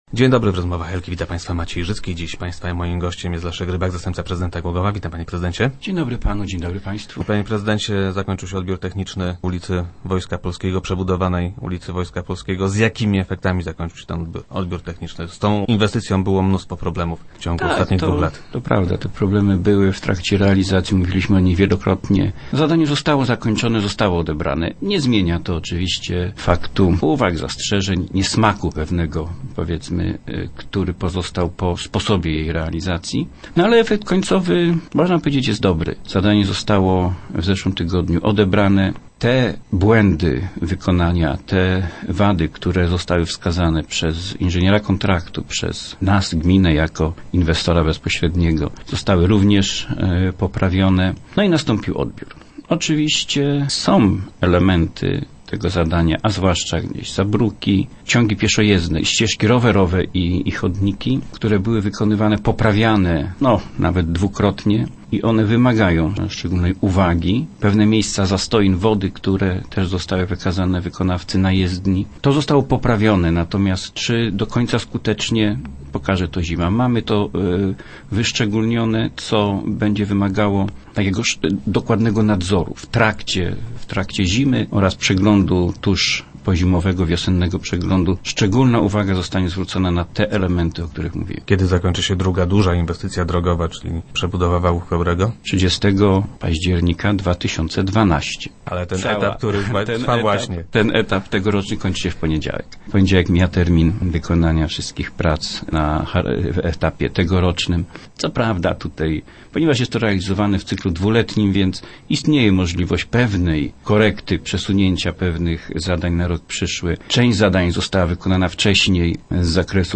- Głogów zaoszczędzi około dwustu tysięcy złotych - informuje Leszek Rybak, zastępca prezydenta Głogowa, który był dziś gościem Rozmów Elki.